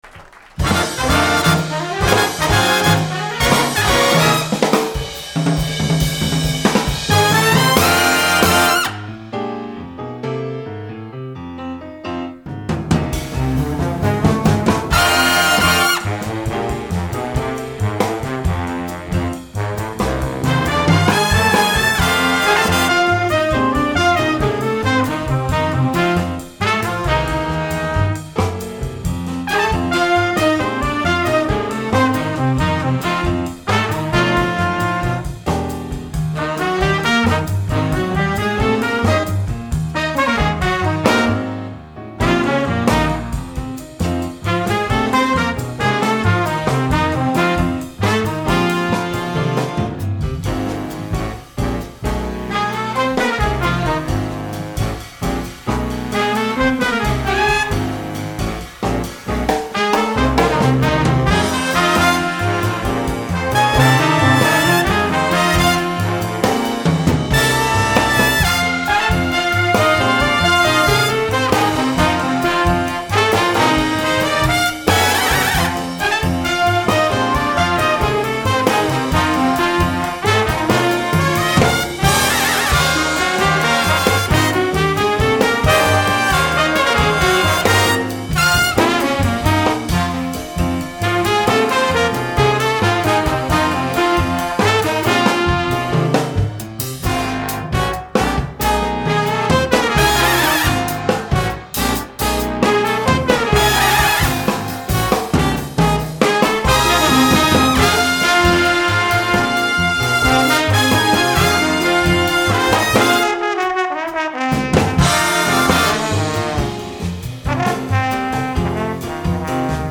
Home > Music > Jazz > Bright > Floating > Chasing